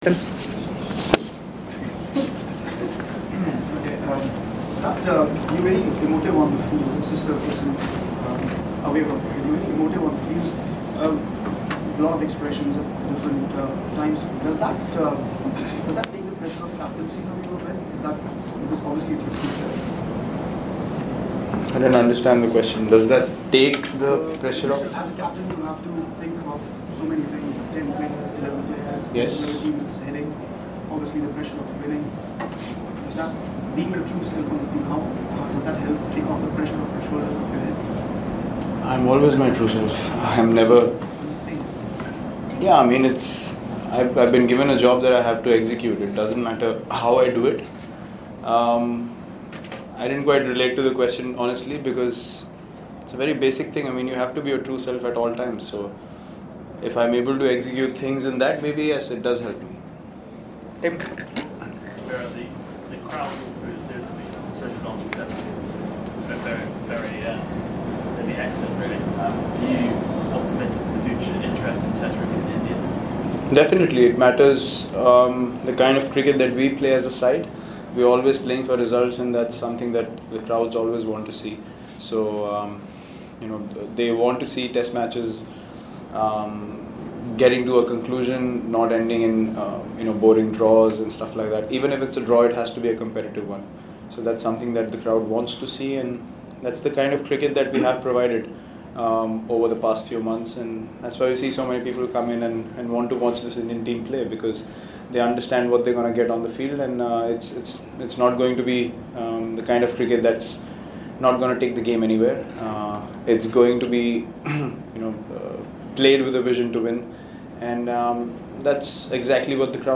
LISTEN: Captain Virat Kohli speaks about comprehensive 4-0 win against England.